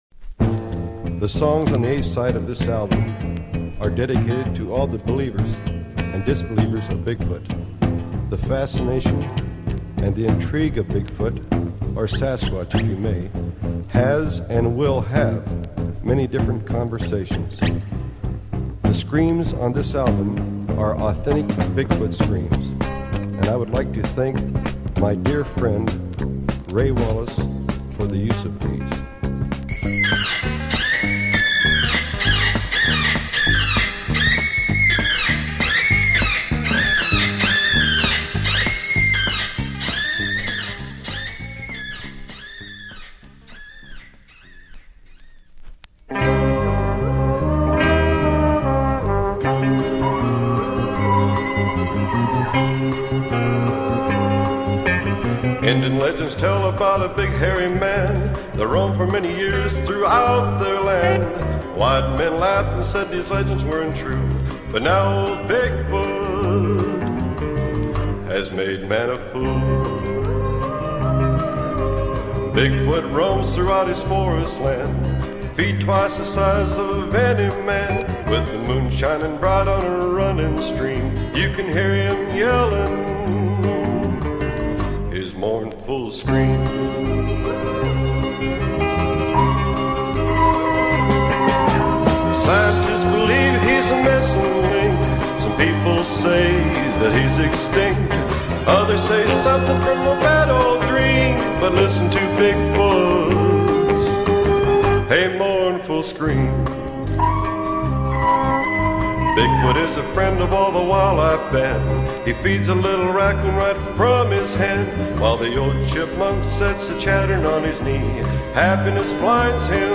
And it truly is a haunting wail worth waiting for.